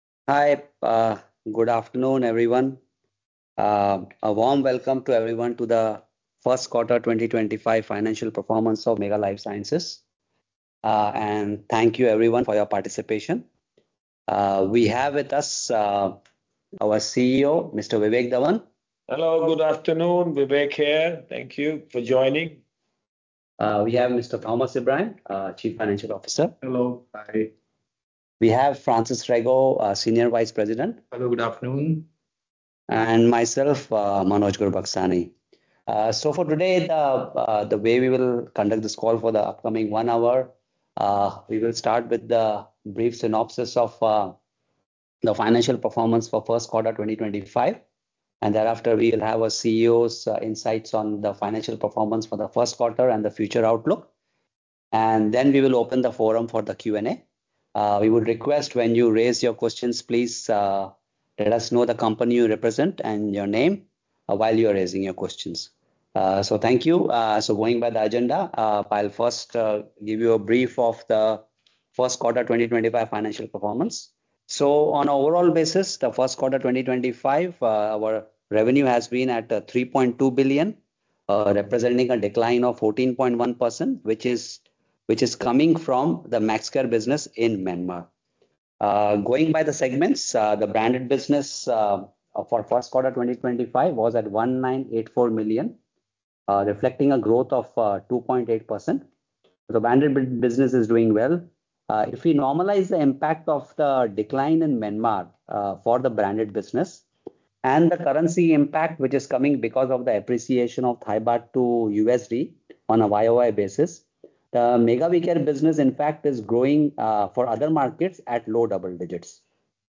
1q25-earnings-conference-call.mp3